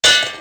shipAttach.wav